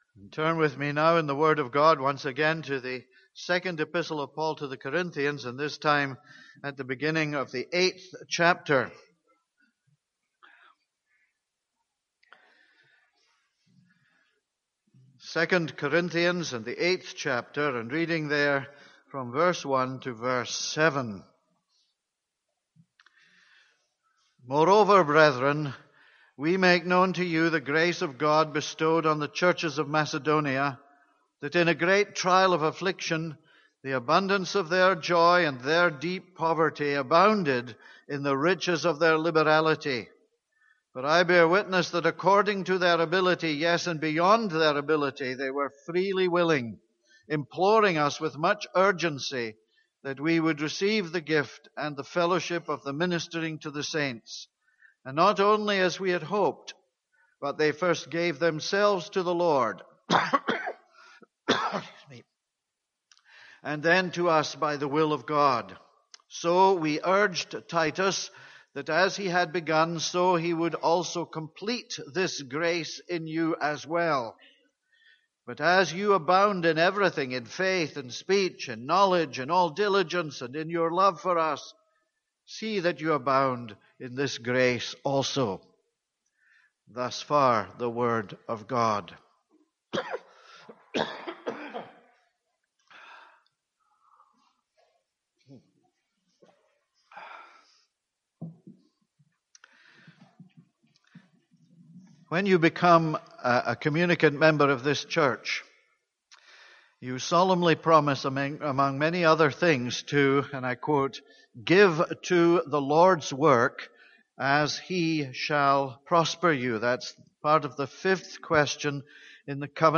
This is a sermon on 2 Corinthians 8:1-7.